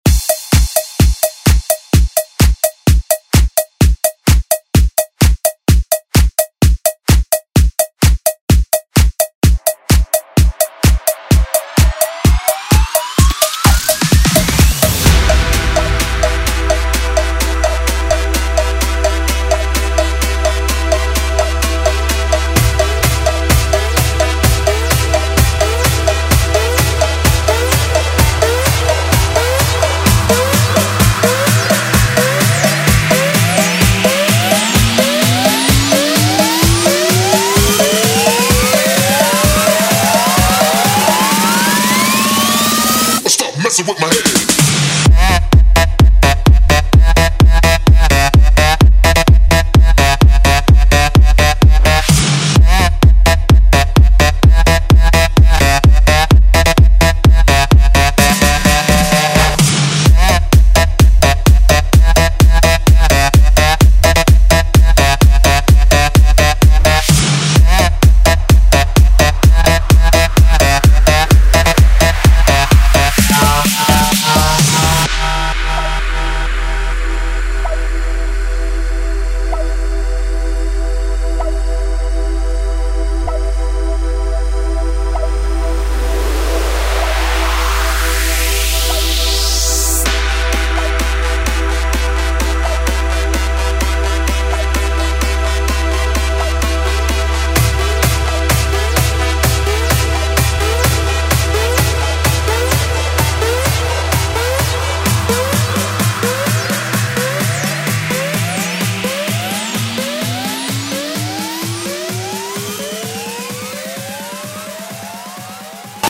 Electronic Pop Disco Music Extended ReDrum Clean 126 bpm
Genre: 80's
Clean BPM: 126 Tim